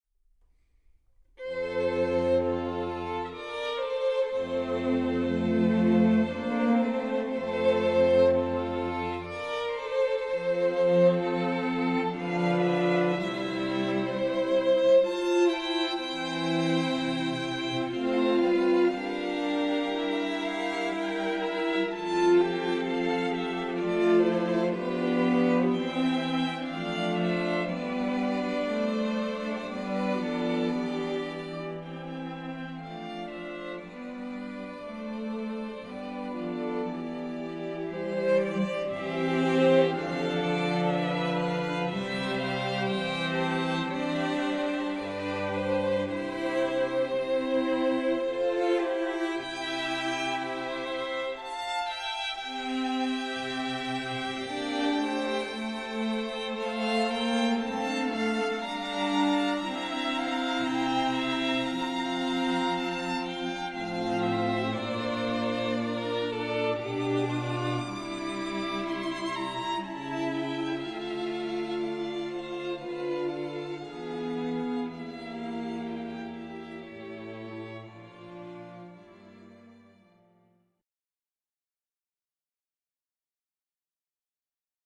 Voicing: String Quintet